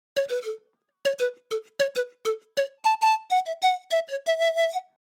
Poppy Panflute
Poppy Panflute is a free sfx sound effect available for download in MP3 format.
yt_uY8ONkY5_gc_poppy_panflute.mp3